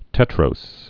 (tĕtrōs)